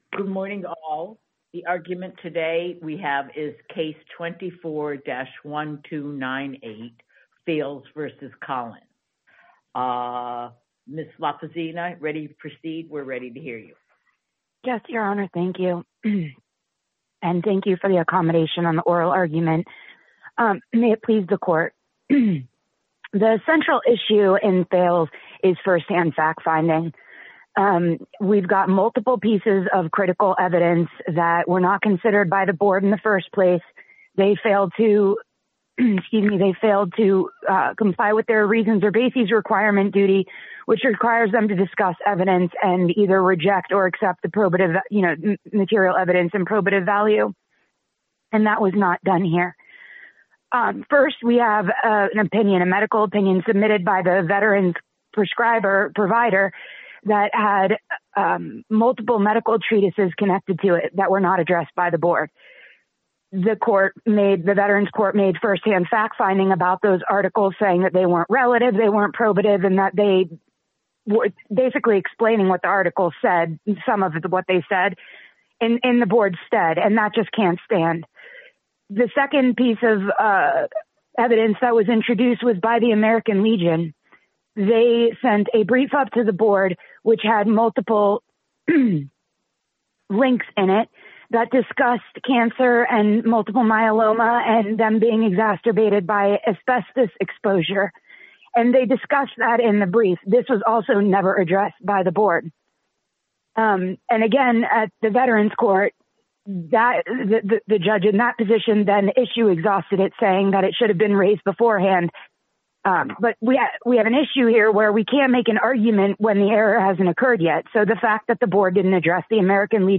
Federal Circuit Oral Argument AI Transcripts